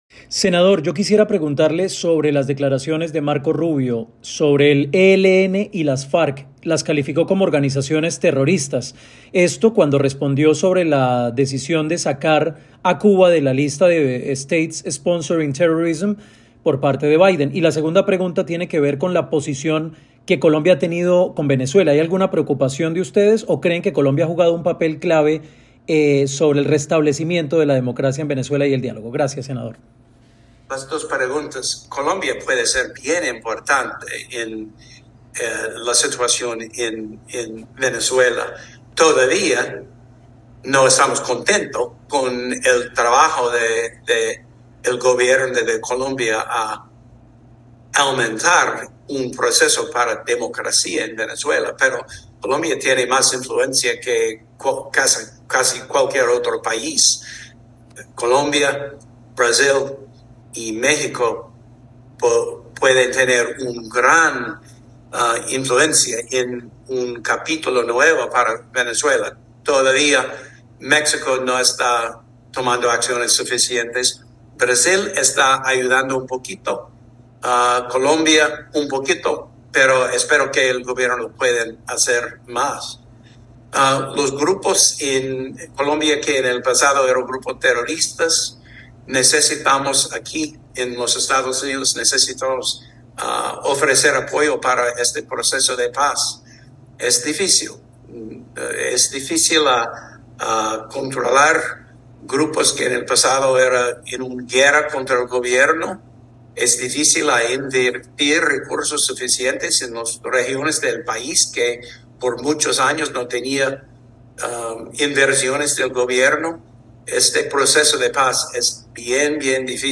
Este viernes, desde Washington DC, el senador Tim Kaine respondió a W Radio sobre la visión y postura que tiene frente al papel de Colombia en la situación de Venezuela.